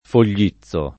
Foglizzo [ fol’l’ &ZZ o ]